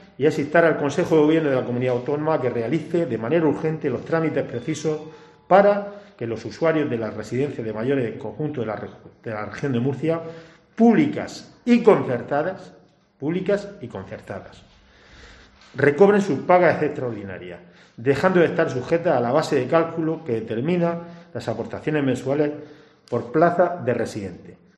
Pedro Sosa, edil de IU Verdes Lorca